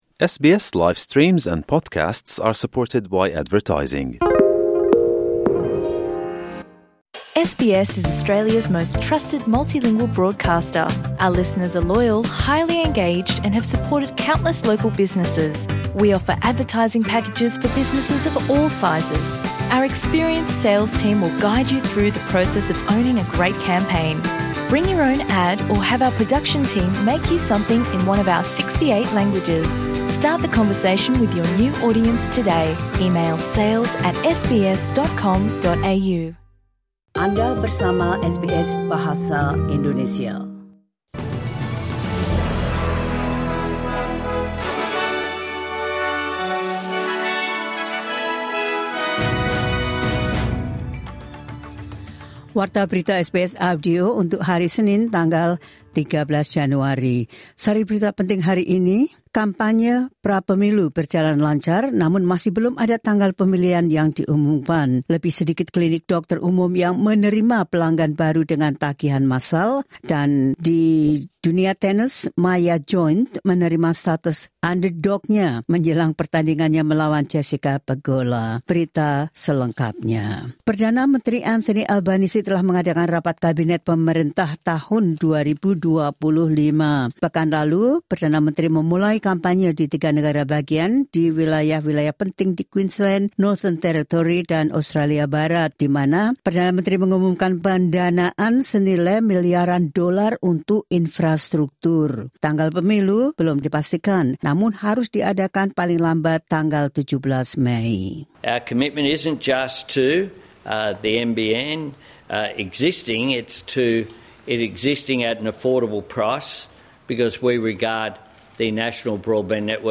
Berita terkini SBS Audio Program Bahasa Indonesia – 13 Januari 2025.